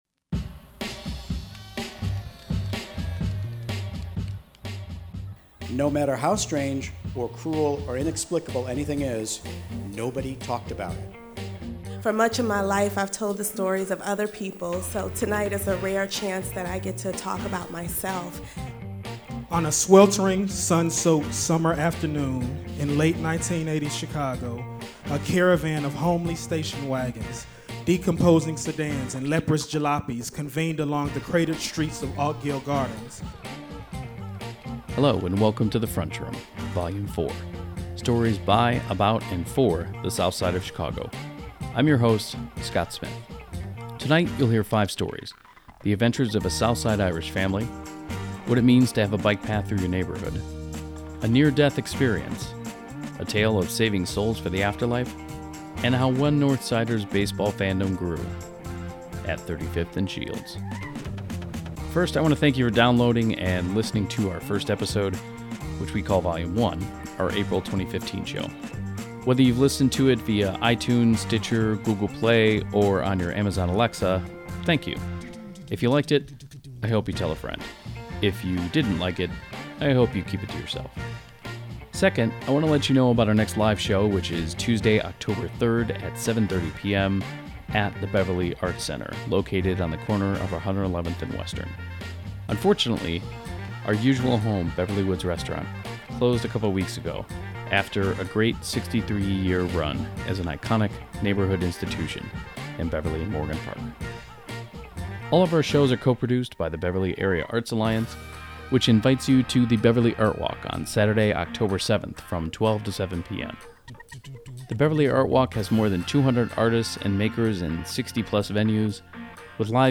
Here is Volume 4 of the The Frunchroom from January 21st, 2016 at O’Rourke’s Office on 111th and Western Avenue.